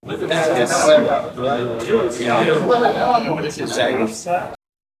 Royalty-free human-voice sound effects
a group of 4 people discussing and talking about a project
a-group-of-4-people-w2lndqfw.wav